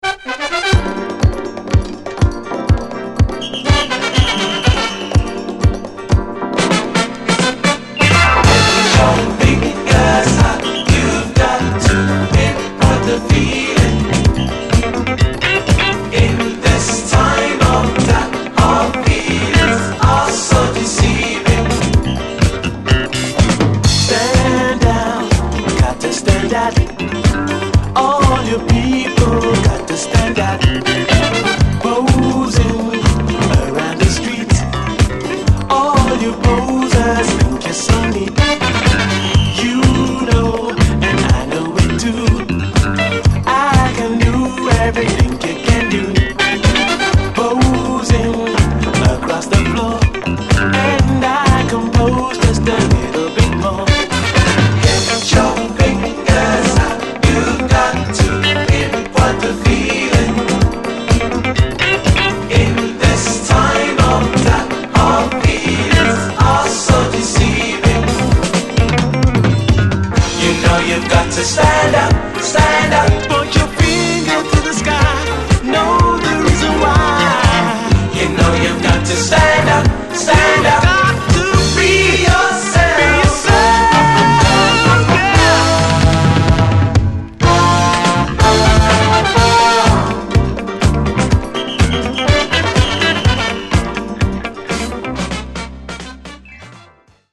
Format: 7 Inch